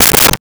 Dresser Drawer Handle 01
Dresser Drawer Handle 01.wav